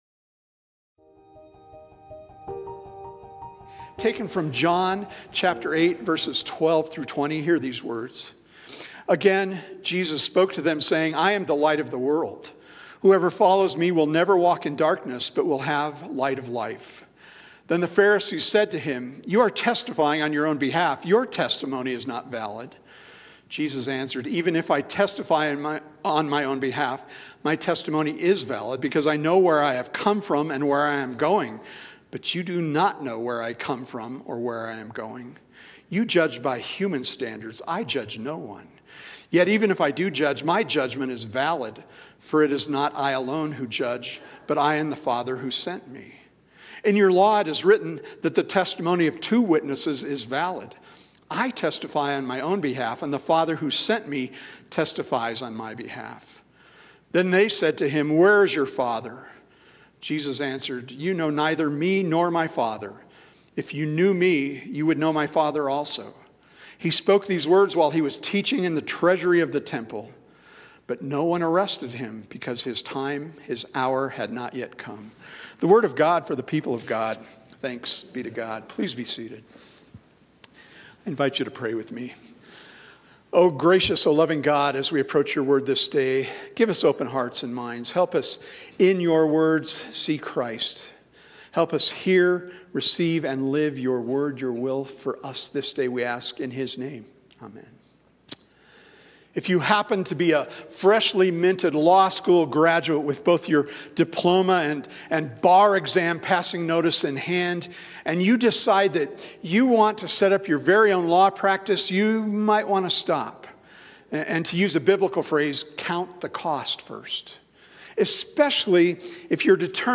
THIS WEEK’S SERMON Blessed With Freedom